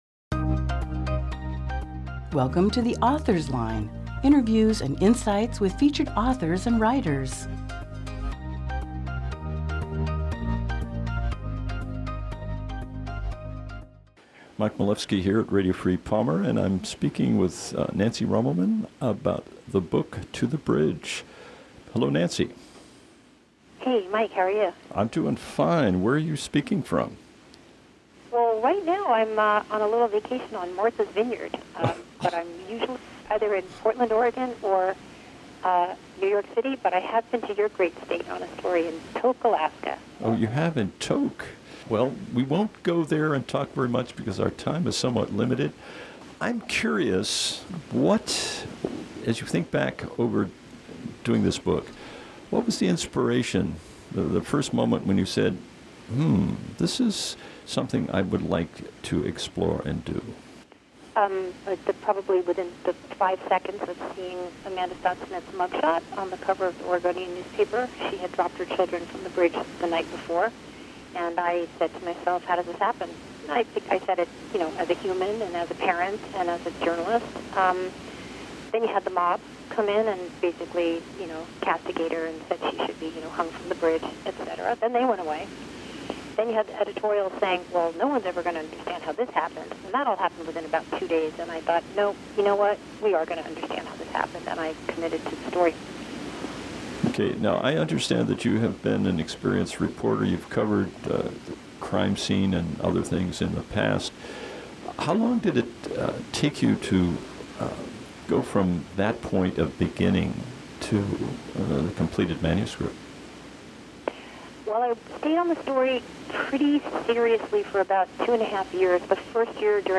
Aug 13, 2018 | Author Interviews